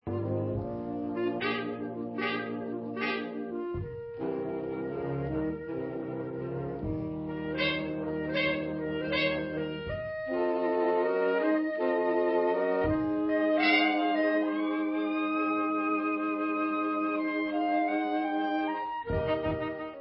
sledovat novinky v oddělení Blues/Swing